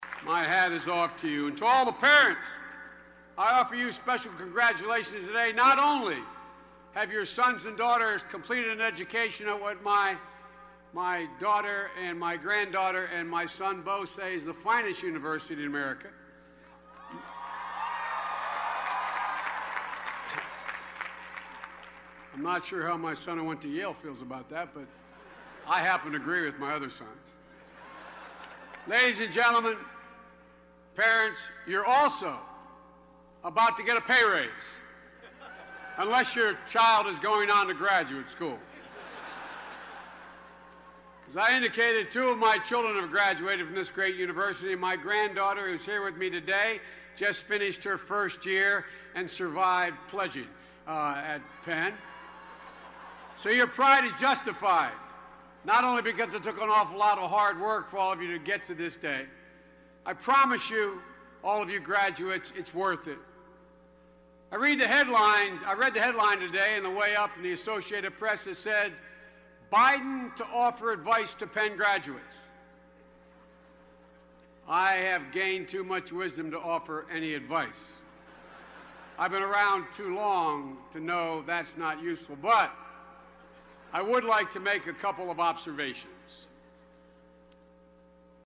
公众人物毕业演讲第405期:拜登2013宾夕法尼亚大学(3) 听力文件下载—在线英语听力室